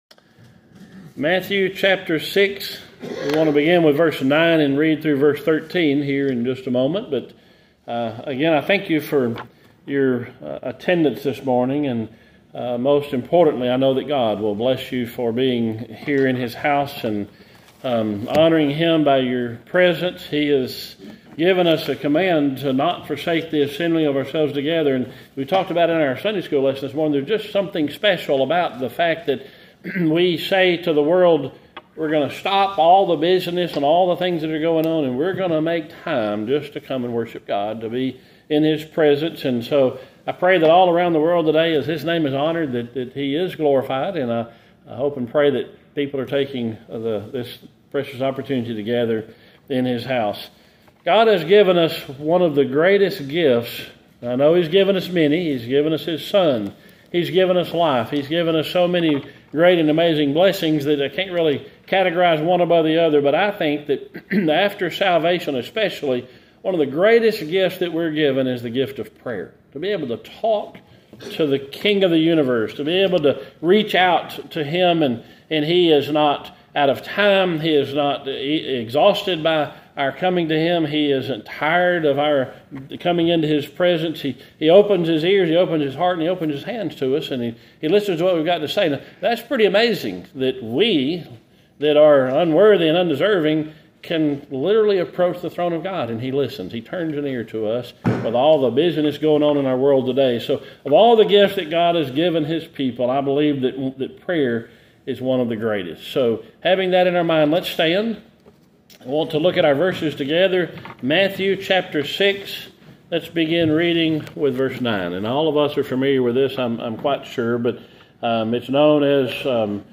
Sermon - Gallatin Missionary Baptist Church